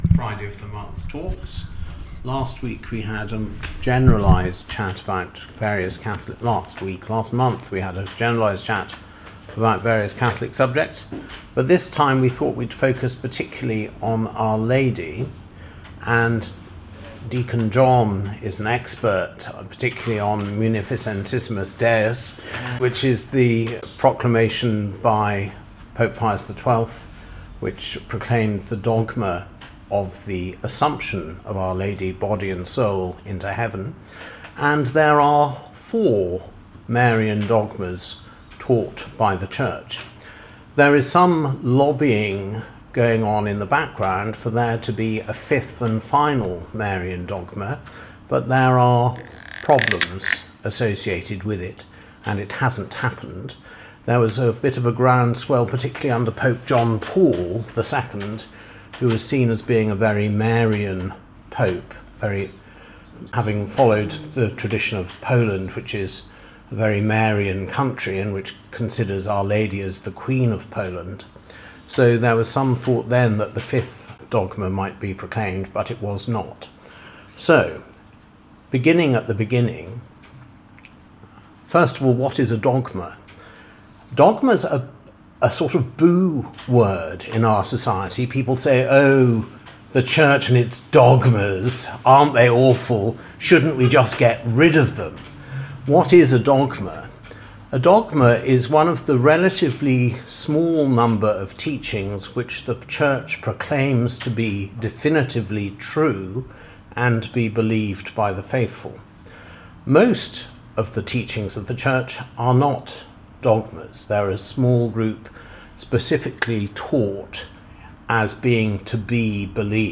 They are usually on an aspect of ministry or other spiritual topic. We start with prayer and praise concluding around 9.00pm. All talks are in the Parish Centre